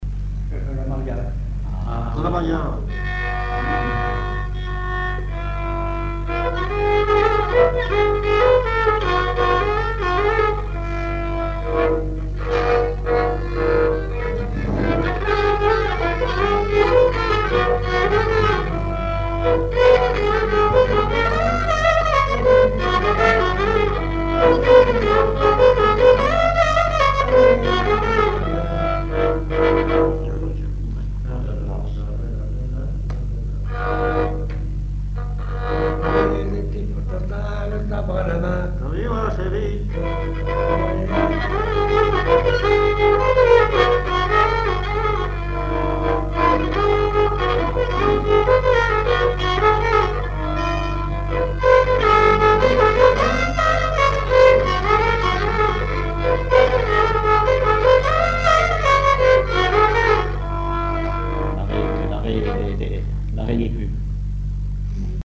L'enfance - Enfantines - rondes et jeux
danse : mazurka
chansons populaires et instrumentaux
Pièce musicale inédite